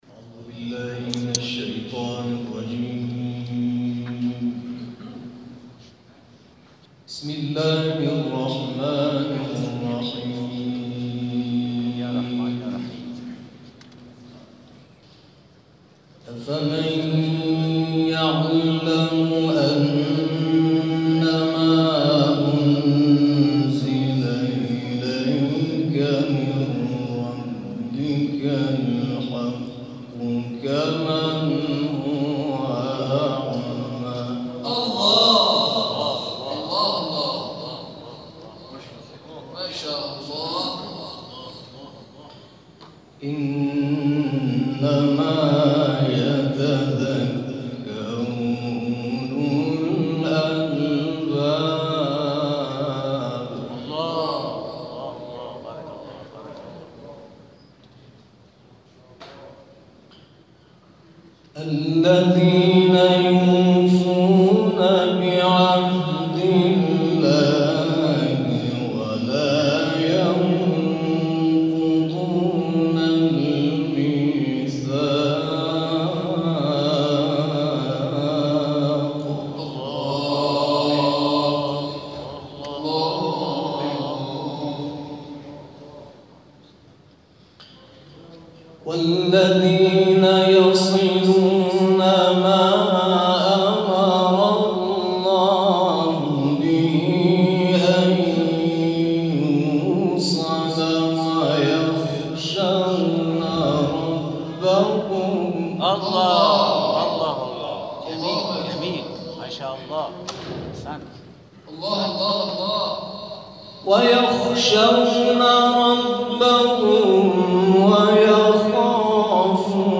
در ادامه تلاوت‌های منتخب این جلسه ارائه می‌شود.